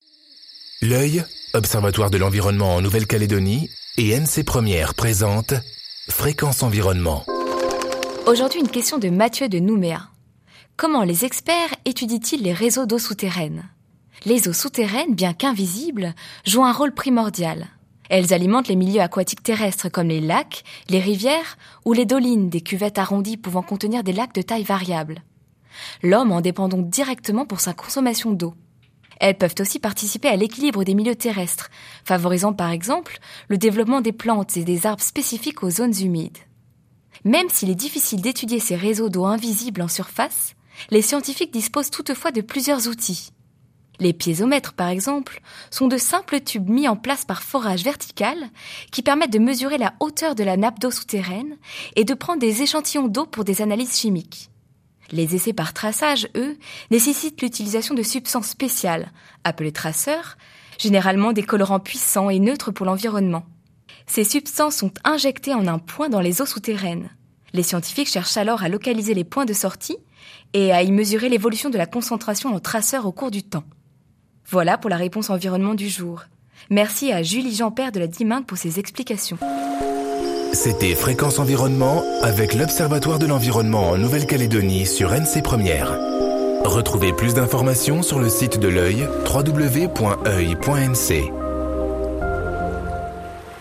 diffusée en décembre 2013 sur NC 1ère